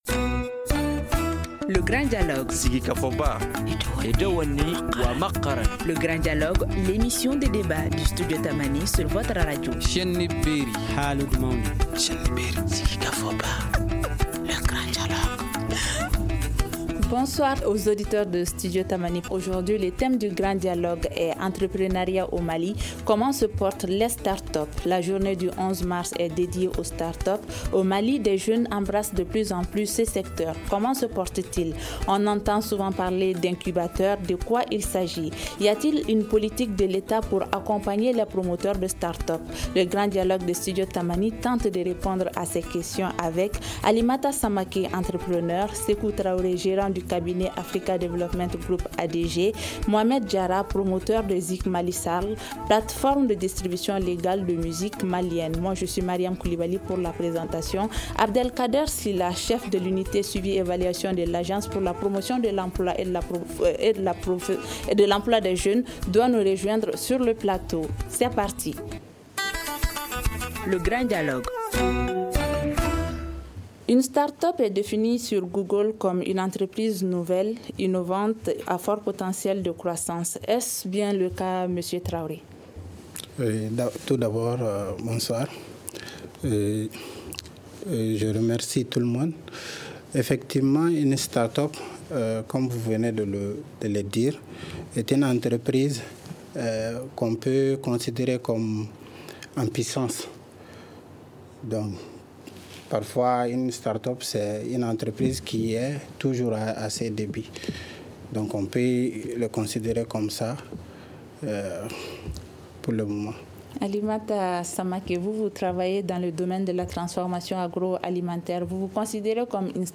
Le Grand Dialogue de Studio Tamani tente de répondre à ces questions avec :